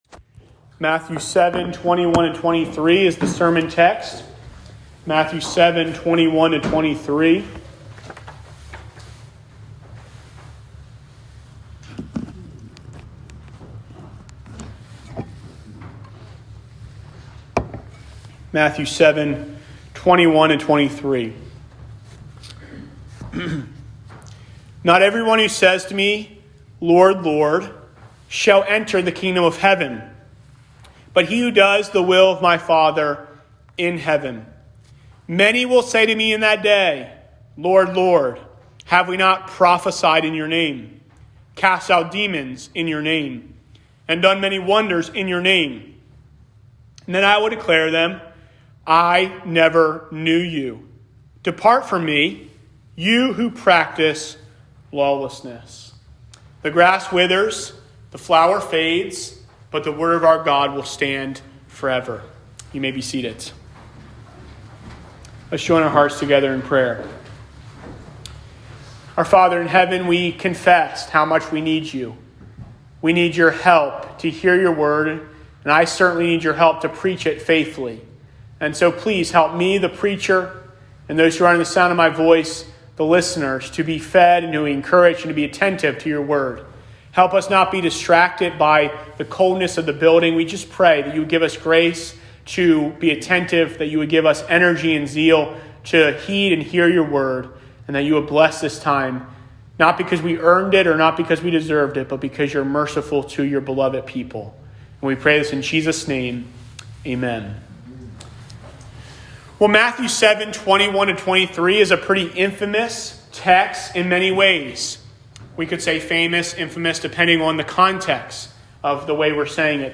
I Never Knew You | SermonAudio Broadcaster is Live View the Live Stream Share this sermon Disabled by adblocker Copy URL Copied!